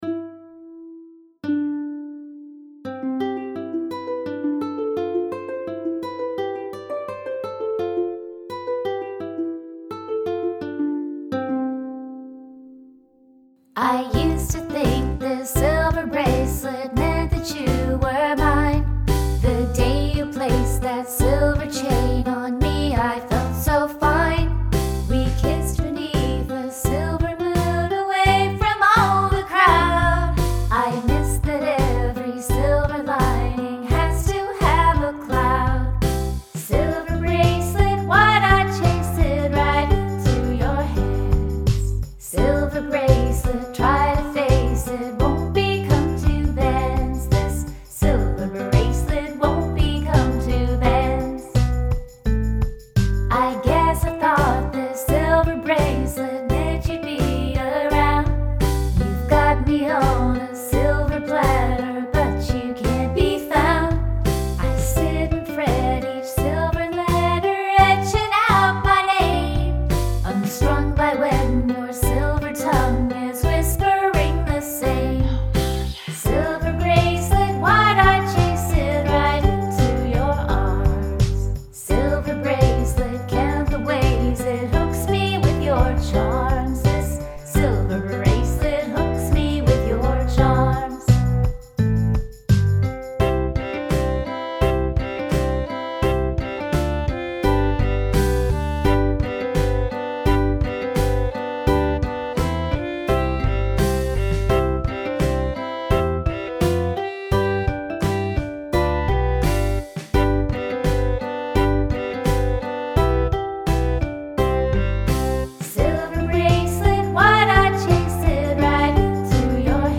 A sound effect appears in the recording of “Silver Bracelet,” and I am honor-bound to credit the source.
C major
• “I played it in the car and it’s so catchy, I found myself happily singing along.”
• “I love the double-dubbing of your voice. Very effective. And I love the song.”
• “You have a good country sound to your voice!”